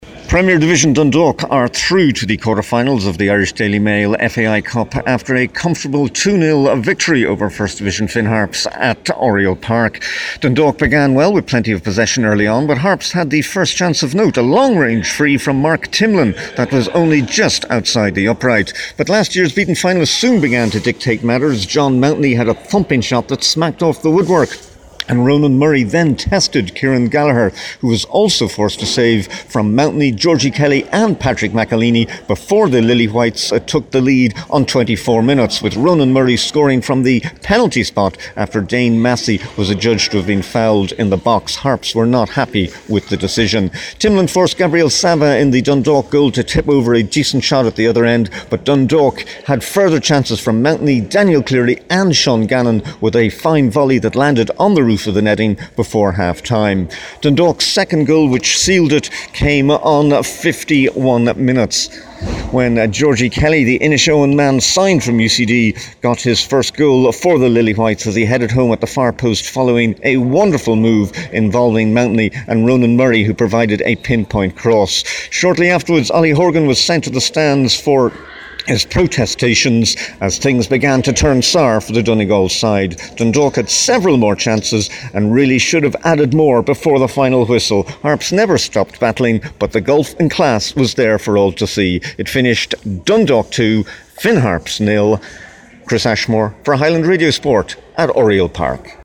Dundalk 2 v 0 Finn Harps: FT Report